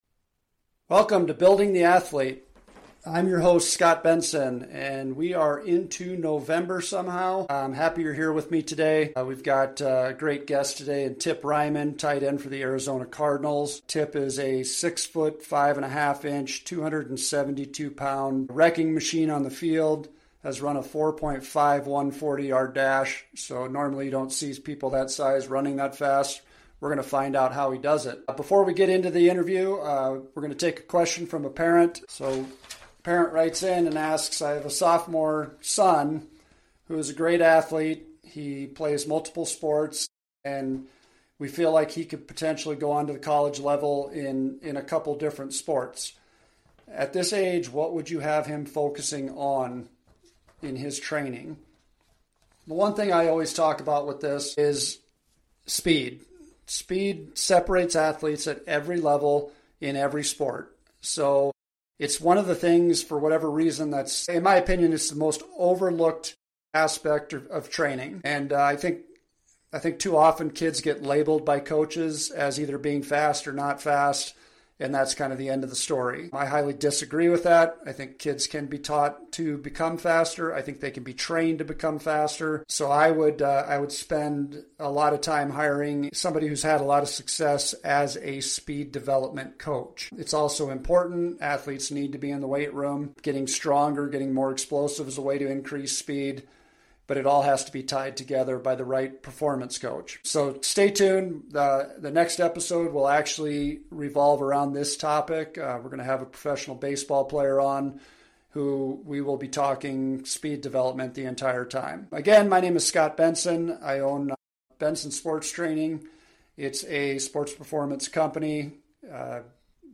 Talk Show Episode, Audio Podcast, Building The Athlete and Ep1